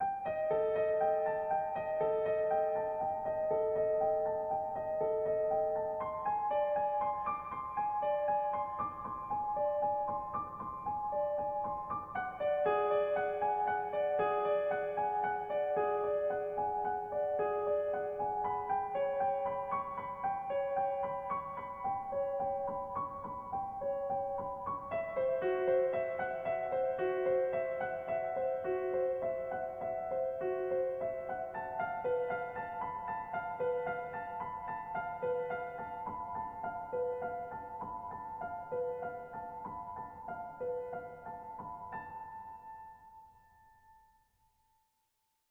描述：快速上升和下降的拨弦乐，并有长的停顿。也许作为游戏或电影的微妙背景音很有意思。
标签： 惊讶的是 上升 微妙 惊讶 背景 哎呀 谨慎 时刻 快速 弹拨 电影 场景 拨弦 序列 向上上下
声道立体声